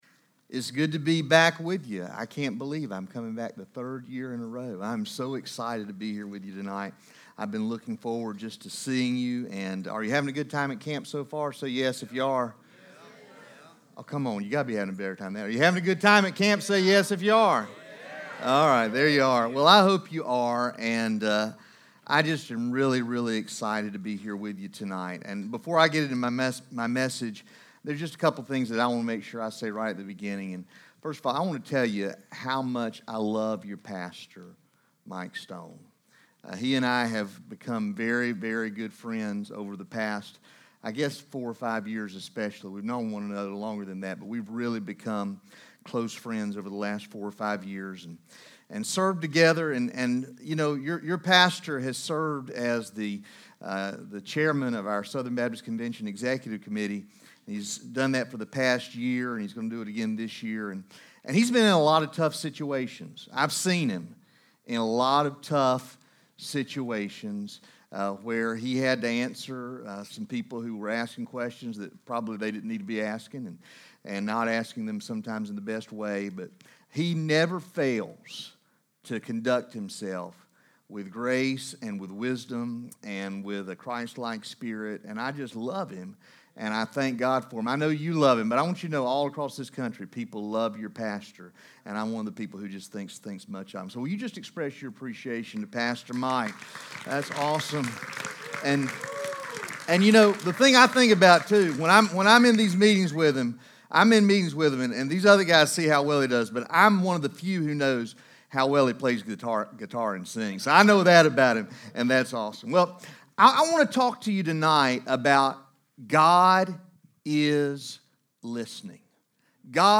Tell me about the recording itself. From the evening session of ESM Summer Camp on Monday, June 24, 2019